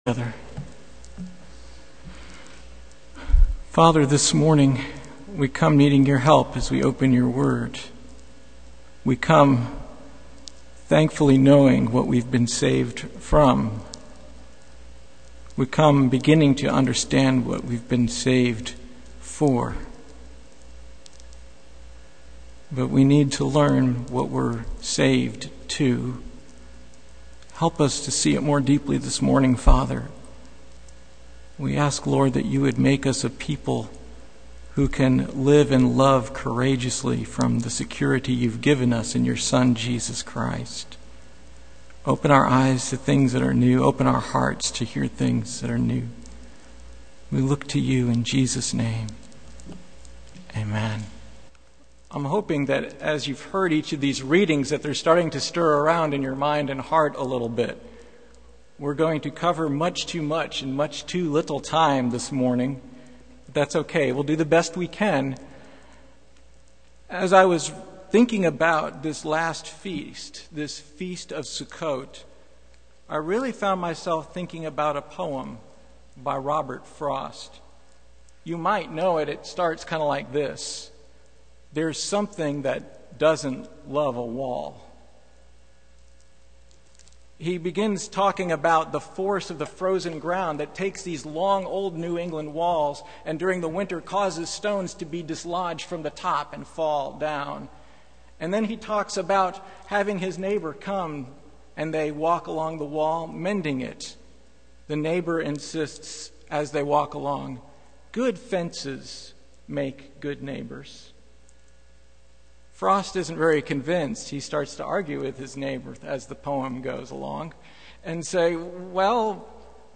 Passage: Leviticus 23:33-44 Service Type: Sunday Morning